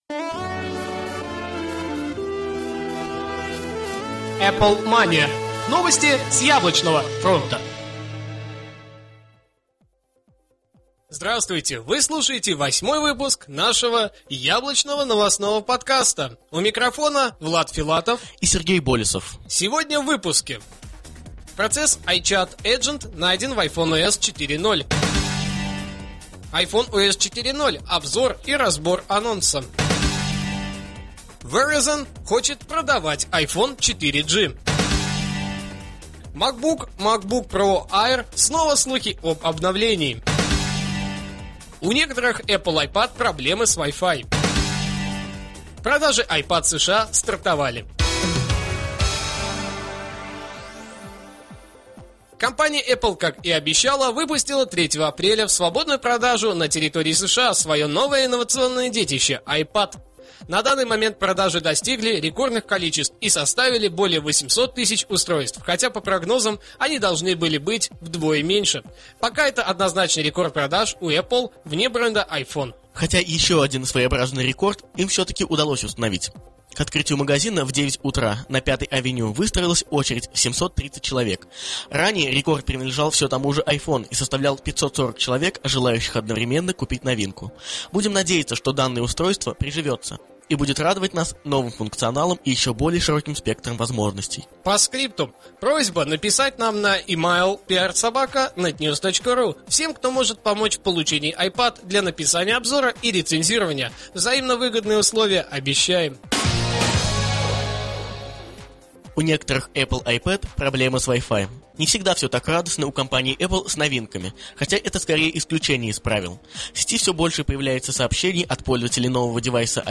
Жанр: новостной Apple-podcast
stereo